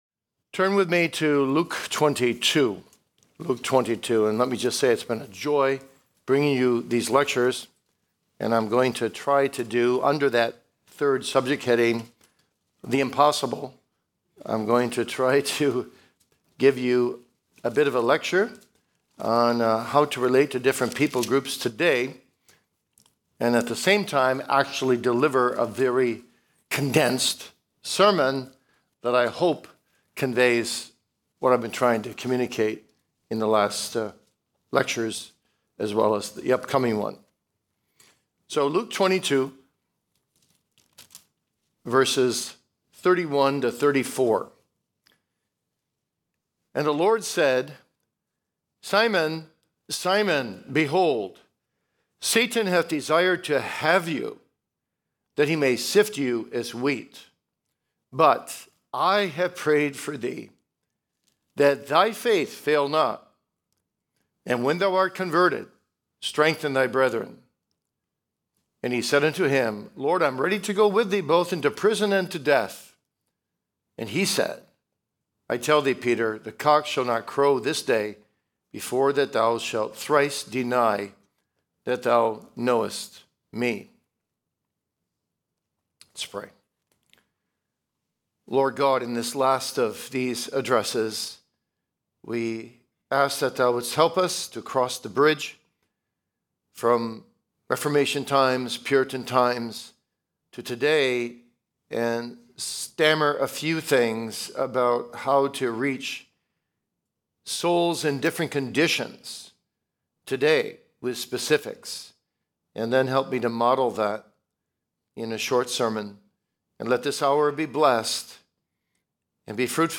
2026 E. Y. Mullins Lectures: The Experiential Aspect of Preaching